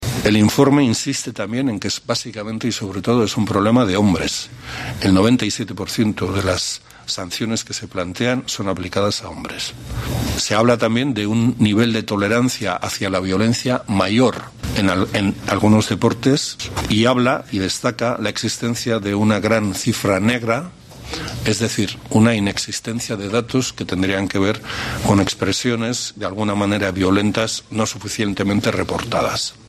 Bingen Zupiria, consejero de Cultura, sobre la violencia en el deporte